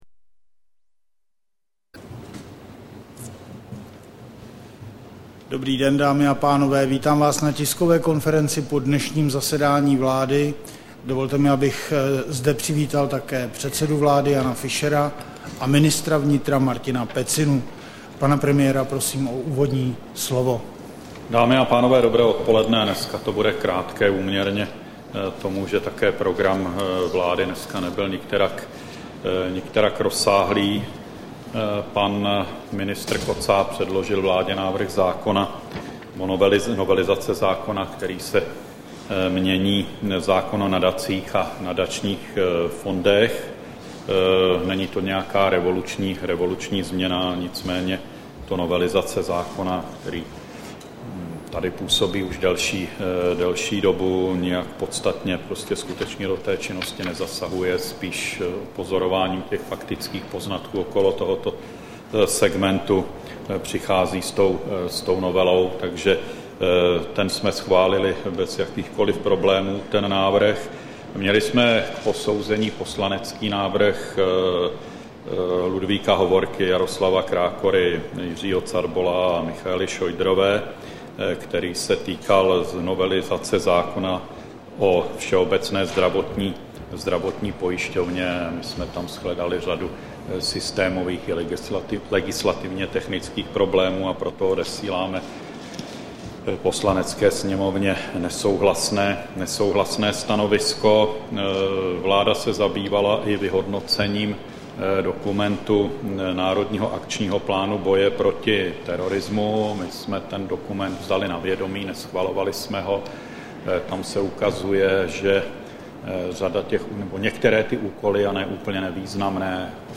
Tisková konference po zasedání vlády, 2. listopadu 2009